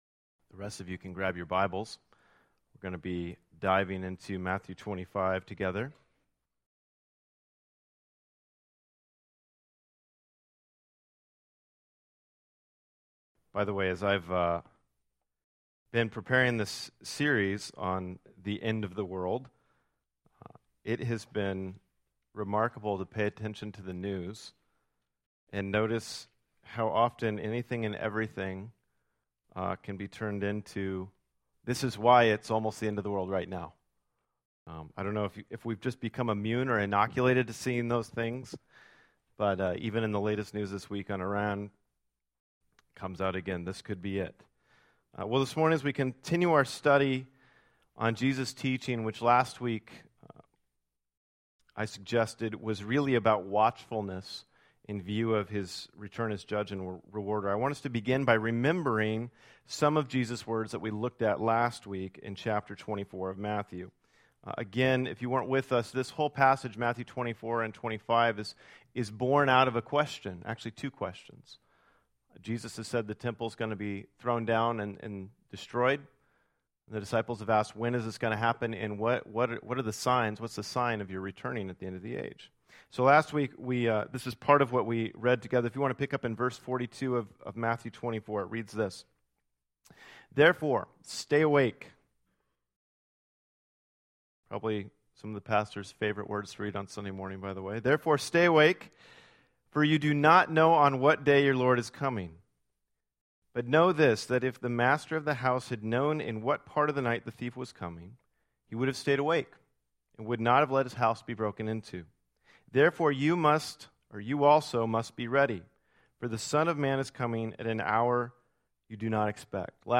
This sermon, focused on Matthew 25:1-13, asks the question: Are you ready for Christ’s return? Similar to the virgins in Jesus’ story, we need to not just look ready, but be truly ready for his return.